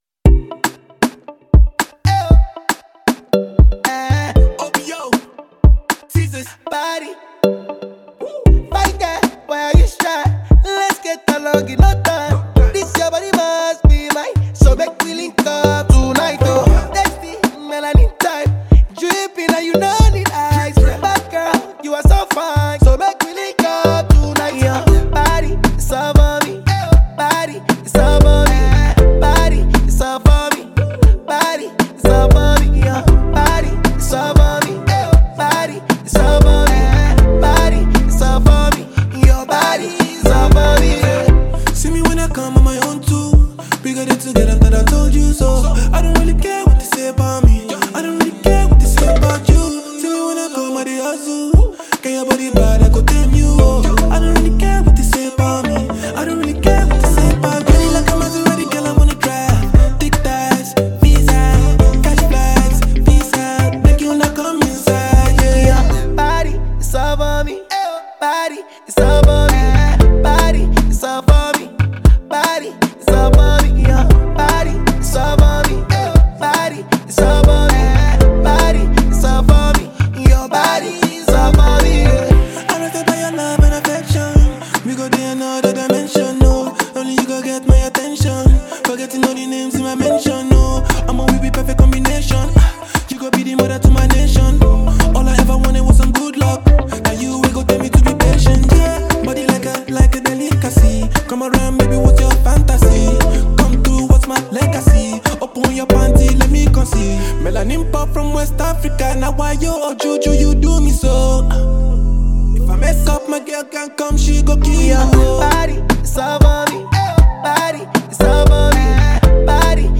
catchy single